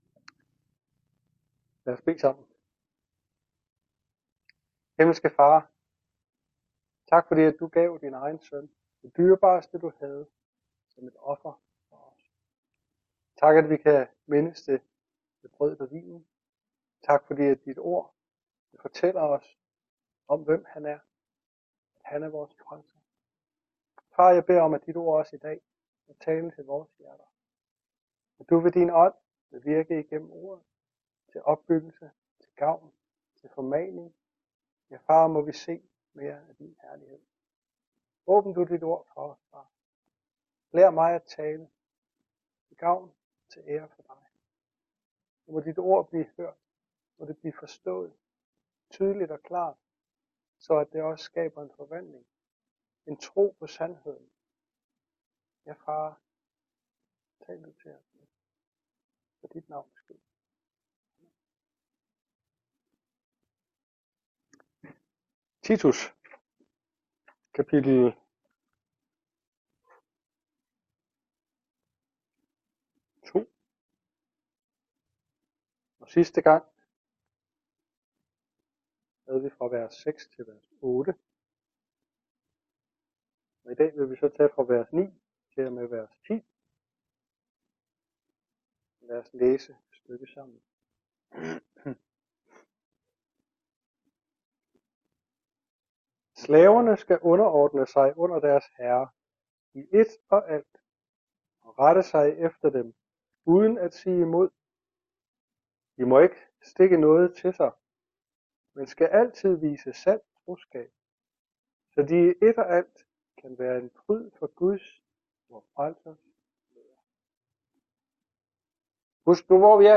Taler